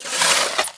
hitditch.mp3